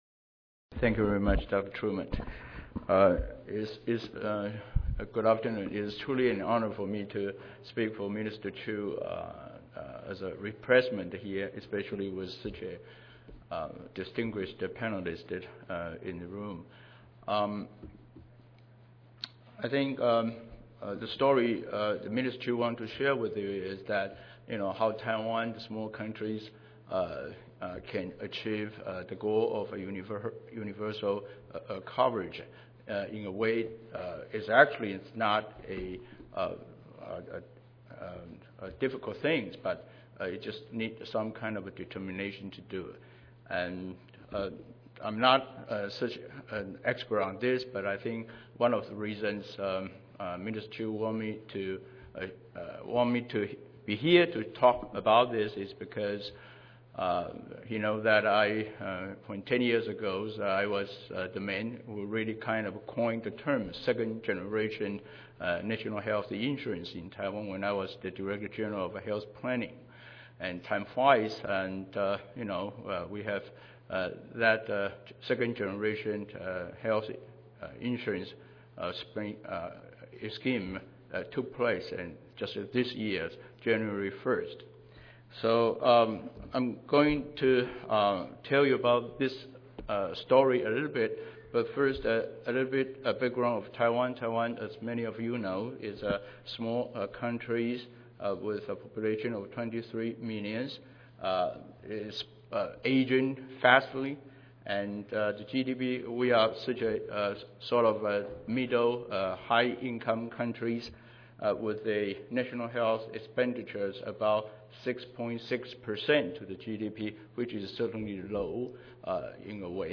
Oral